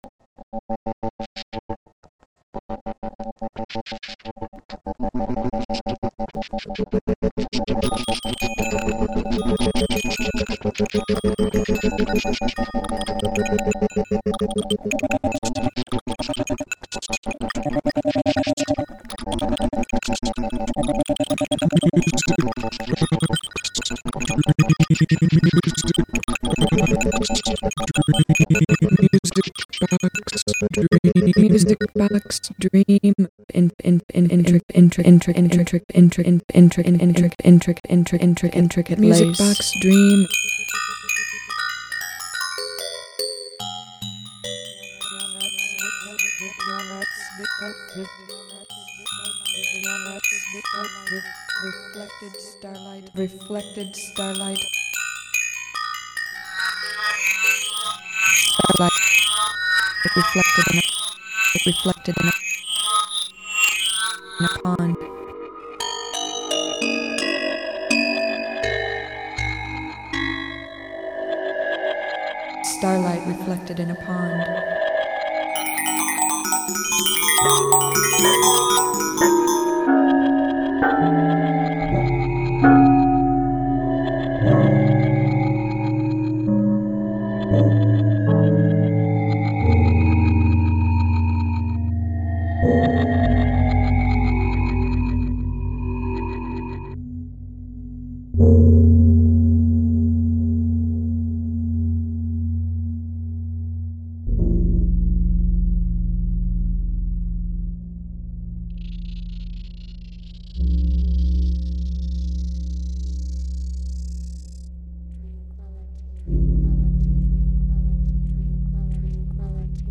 I make electronic music.